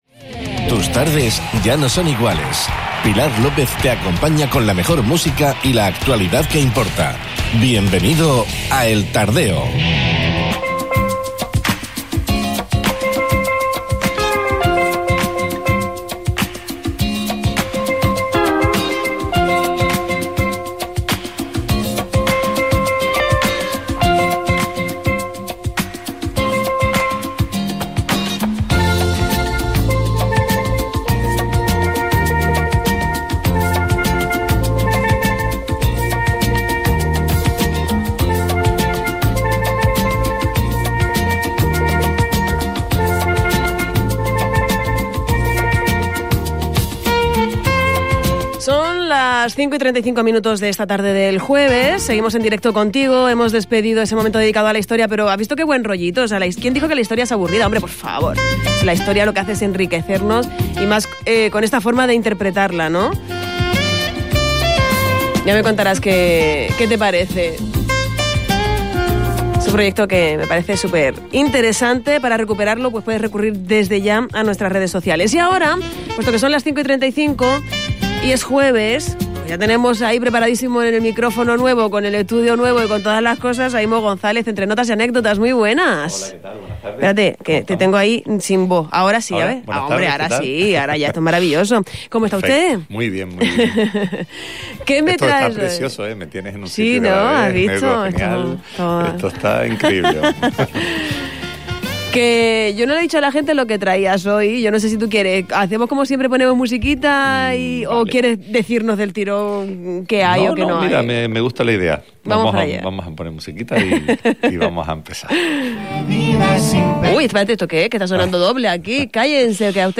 combina poesía y música en esta edición de 'Entre Notas y Anécdotas'